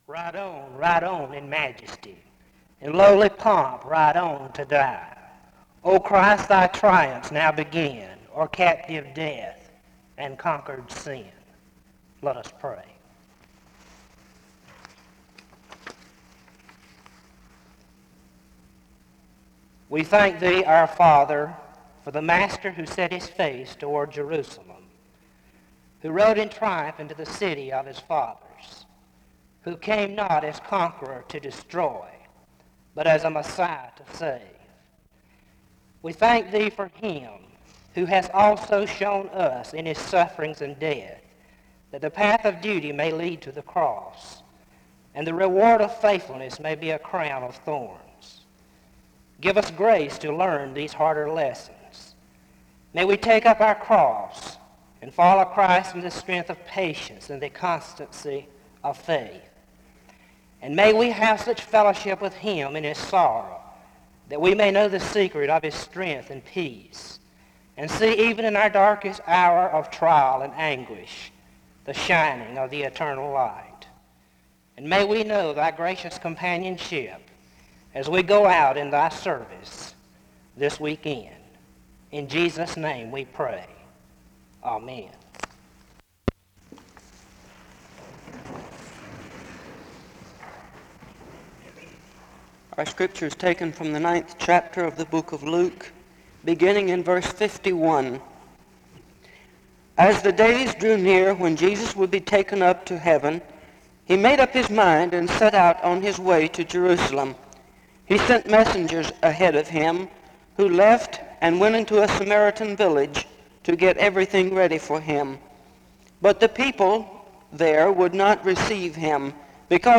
The service begins with a prayer (0:00-1:25).
This chapel has two speakers on Luke 9. The first speaker begins by showing how Jesus is an example of obeying the will of God (2:25-4:16).
This Chapel is distorted periodically throughout.